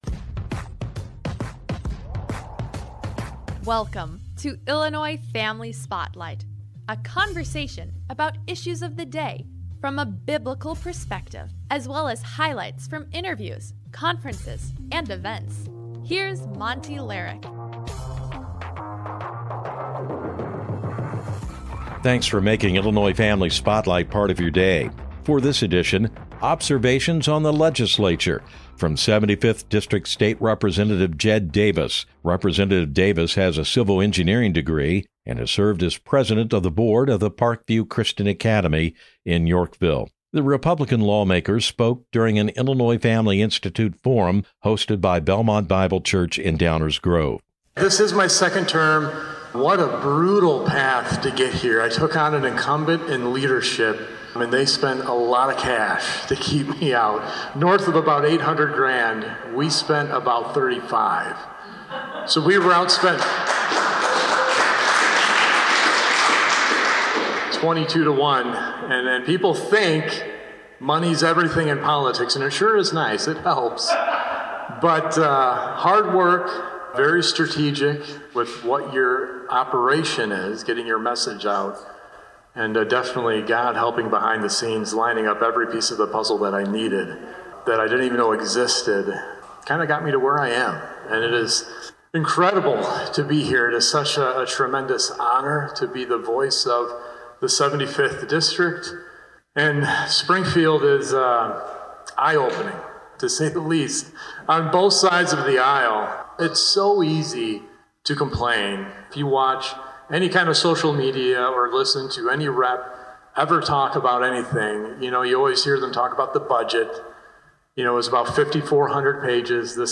Illinois Family Institute recently hosted a forum at Belmont Bible Church in Downers Grove, and this episode of Spotlight features remarks from Jedd Davis at aformentioned event.